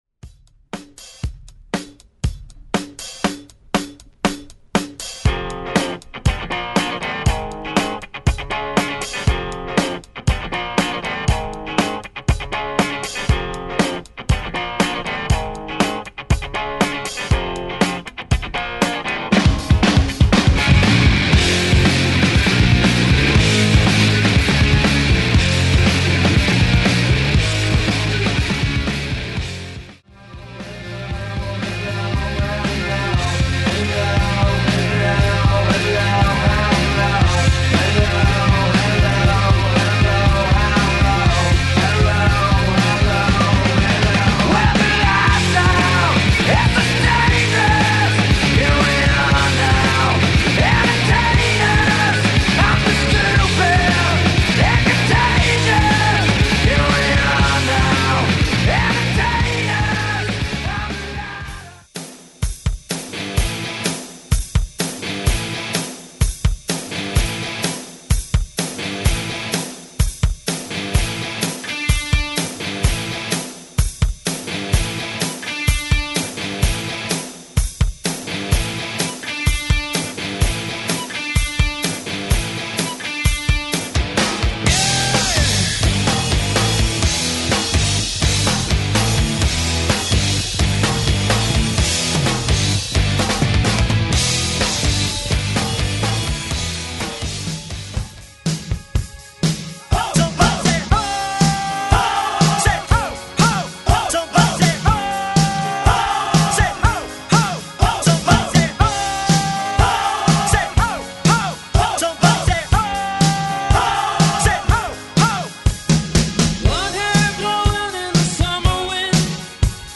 Genre: 80's
Clean BPM: 125 Time